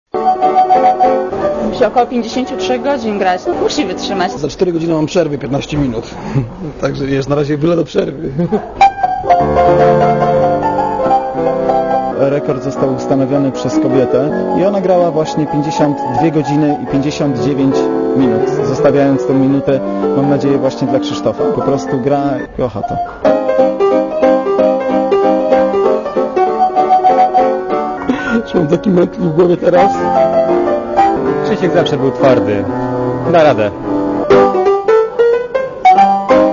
Przysłuchuje mu się sześcioosobowe jury.
Będą to standardy światowe i polskie oraz muzyka filmowa, wszystko w konwencji jazzowej.
pianistarekord.mp3